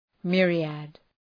Προφορά
{‘mırıəd}